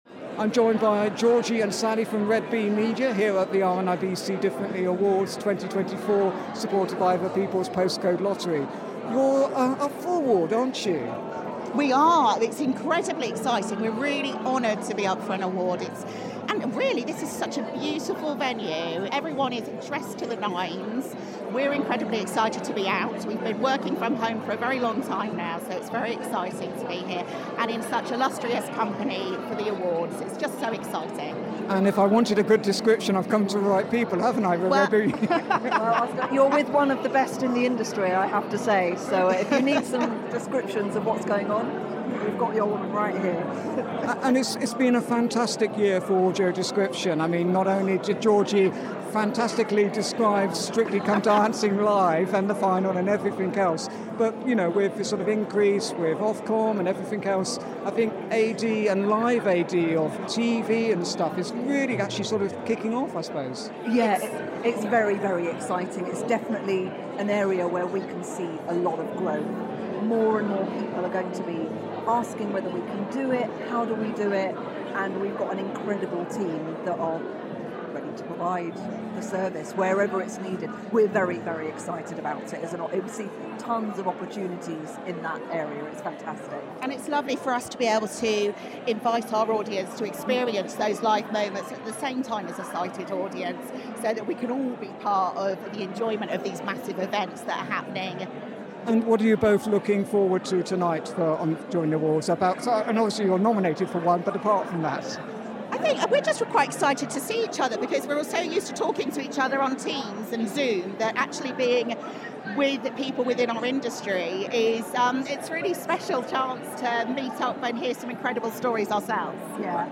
Let’s hear from some of those in attendance at last night’s event, moments before everything started!